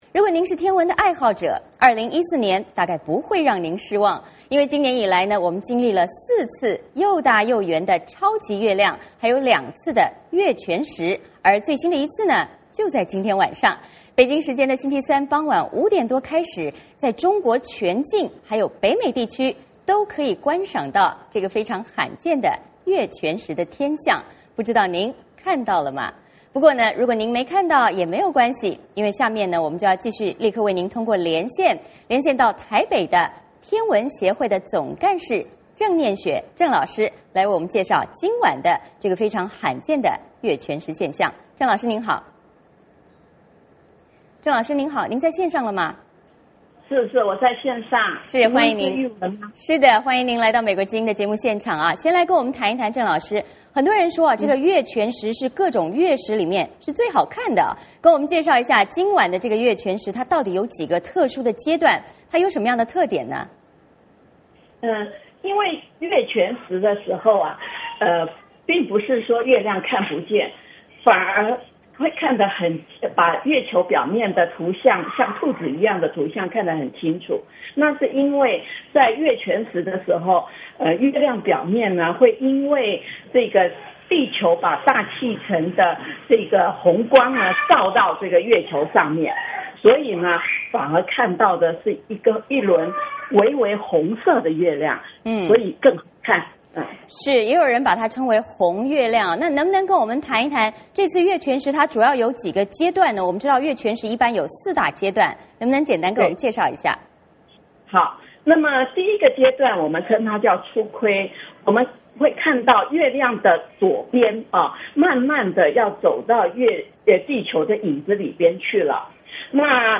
VOA连线：罕见月全食，中国全境可看“红月亮”